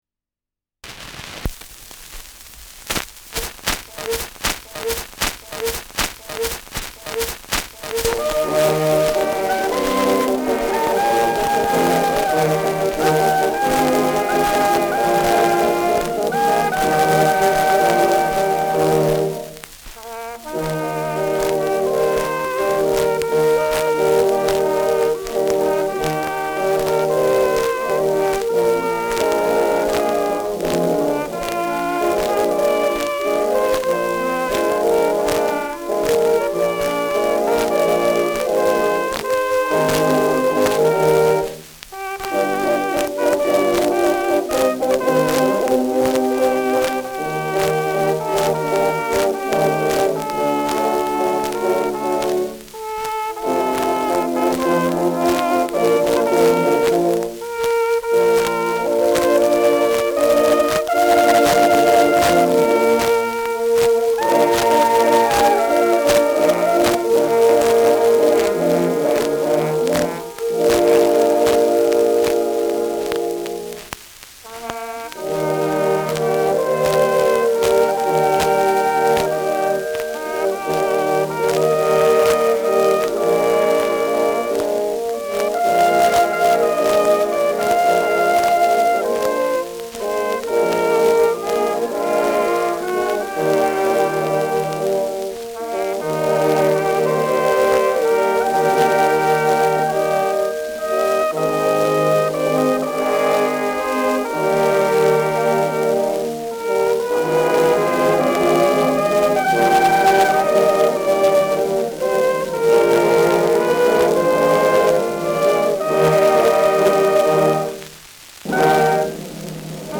Schellackplatte
Hängt zu Beginn : Starkes Grundrauschen : Nadelgeräusch : Verzerrt an lauten Stellen : Gelegentlich leichtes bis stärkeres Knacken
Militärmusik des k.b. 21. Infanterie-Regiments, Fürth (Interpretation)
Hörbar Möbelgeräusche zum Schluss.